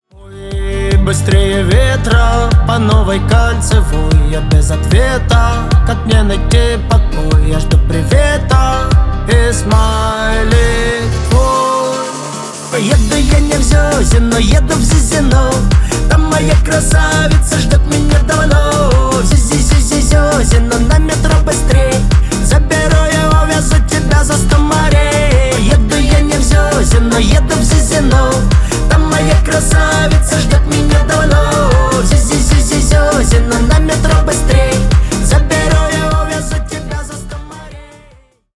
• Качество: 320 kbps, Stereo
Поп Музыка
милые
весёлые